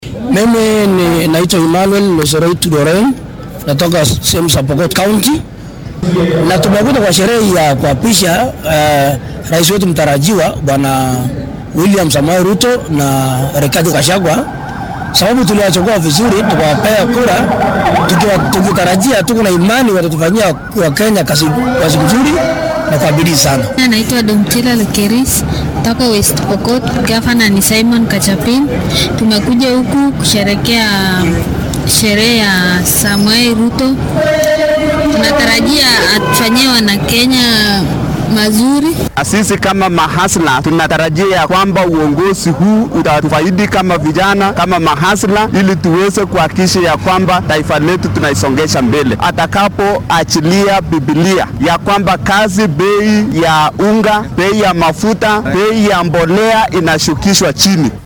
Qaar ka mid ah dadweynaha ku nool gudaha wadanka ayaa muujiyay dareenkooda ku aaddan waxyaabaha ay rajeynayaan inuu diiradda saaro maamulka cusub ee William